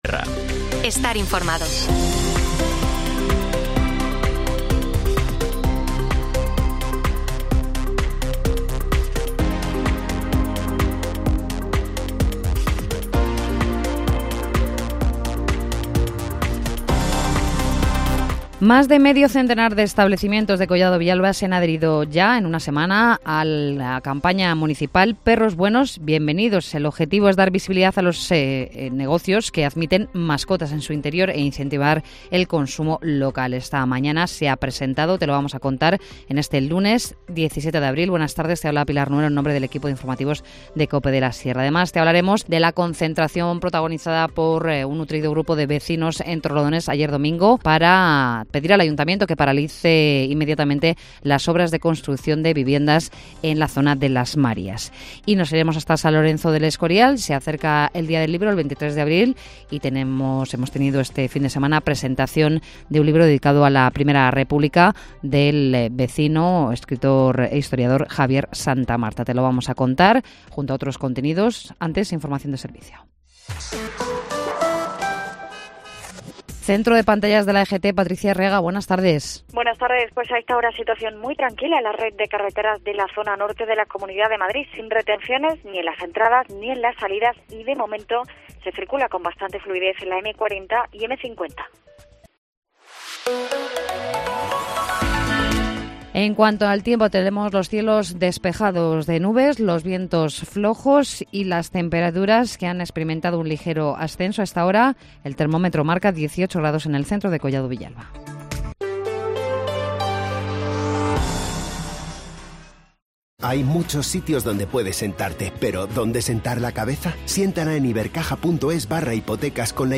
INFORMACIÓN LOCAL
Informativo Mediodía 17 abril